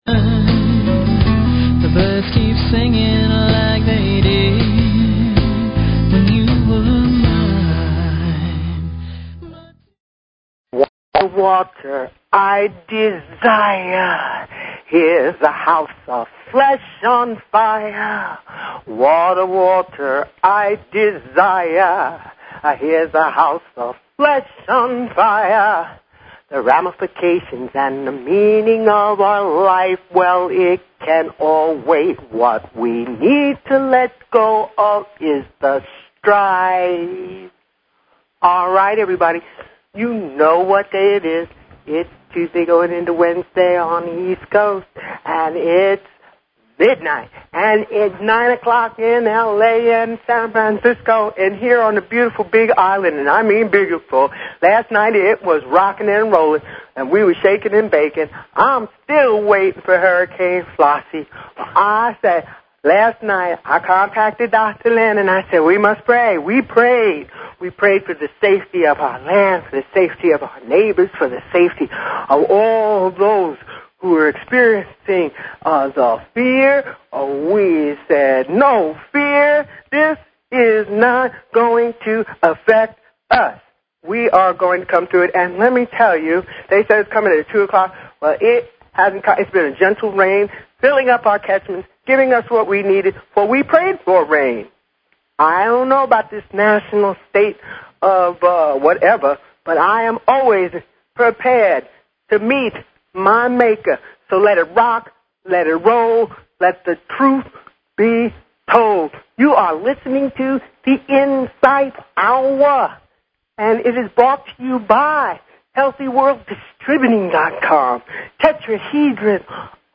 Talk Show Episode, Audio Podcast, The_Insight_Hour and Courtesy of BBS Radio on , show guests , about , categorized as